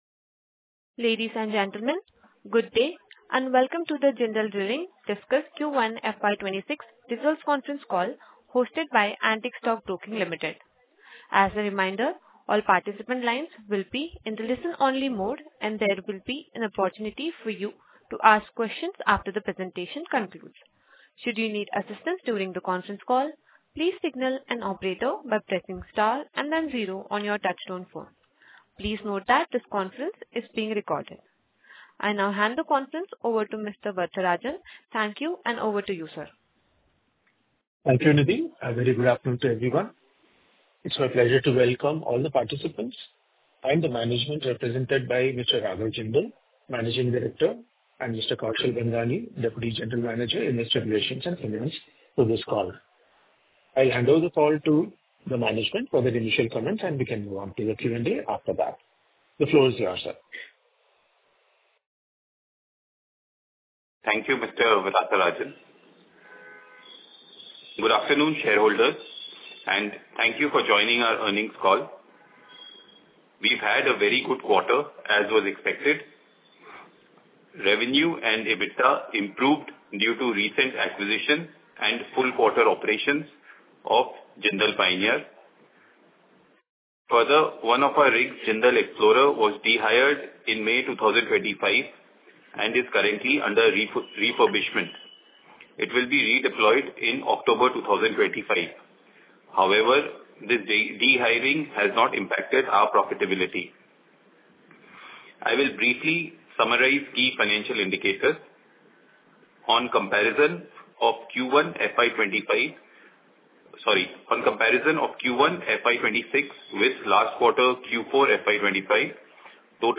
Concalls
Audio-Recording-Q1-FY26-Earnings-Conference.mp3